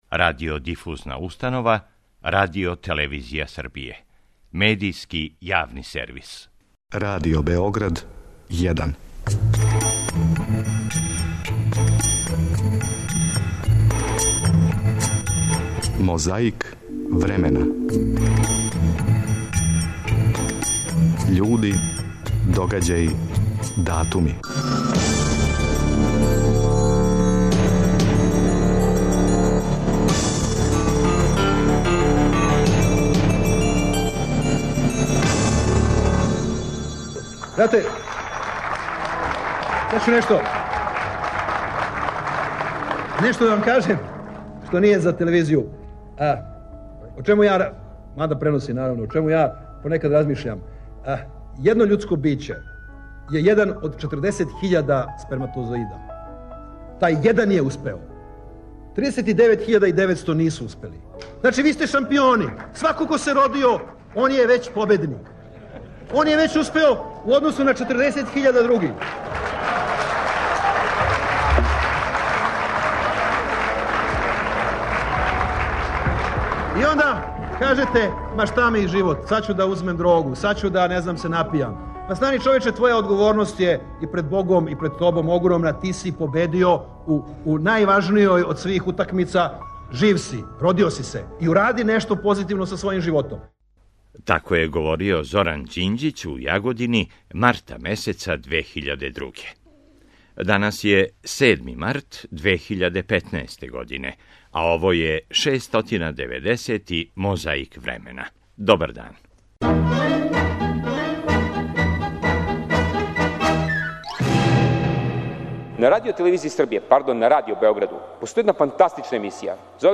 Март месец 2002. године - владина делегација је на турнеји под слоганом 'Србија на добром путу'. Место збивања је Јагодина, а за говорницом Зоран Ђинђић.
Чућемо шта је том приликом, лидер СПО-а Вук Драшковић рекао.
Подсећа на прошлост (културну, историјску, политичку, спортску и сваку другу) уз помоћ материјала из Тонског архива, Документације и библиотеке Радио Београда.